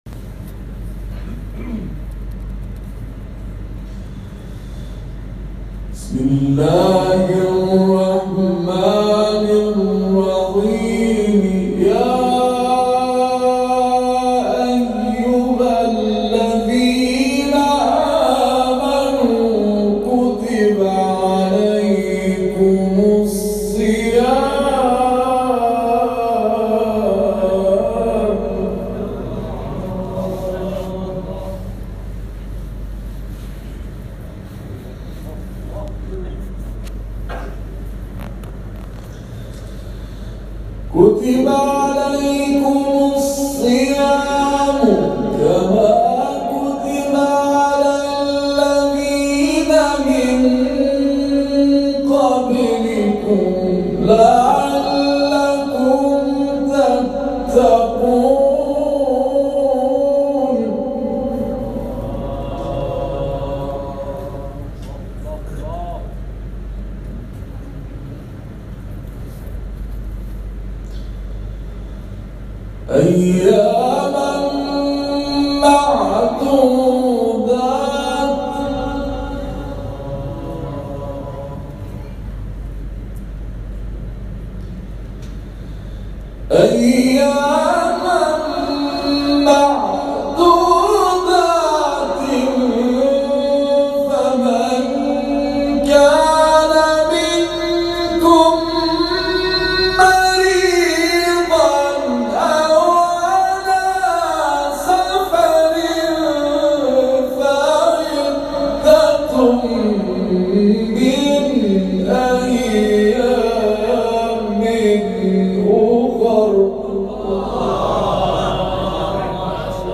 گروه فعالیت‌های قرآنی: محفل انس با قرآن، یکشنبه، 30 خردادماه در بندر امام خمینی(ره) ماهشهر برگزار شد.